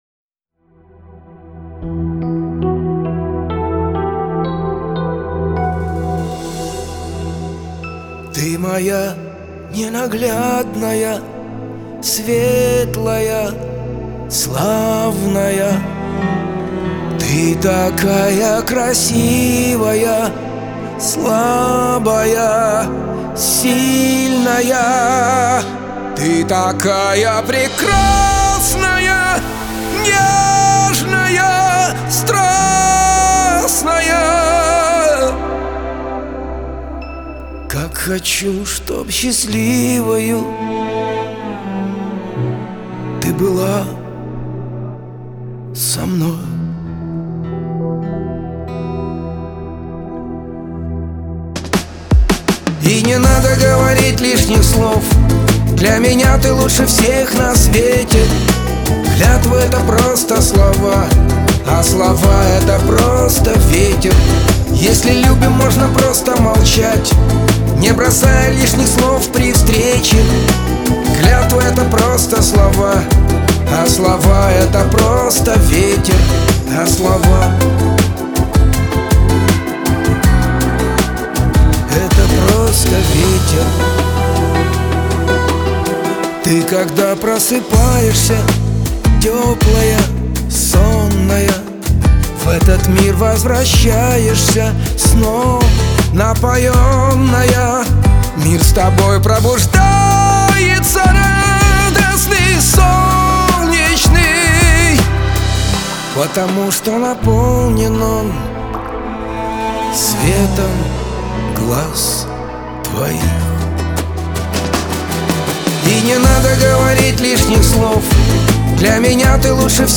pop
эстрада
диско